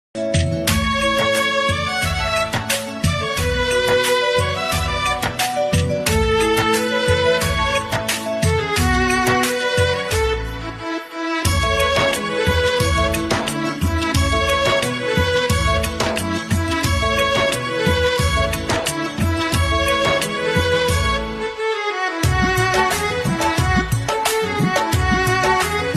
• Patriotic & emotional vibe
• Clear voice & instrumental balance
• High-quality MP3 sound
• Deep patriotic emotions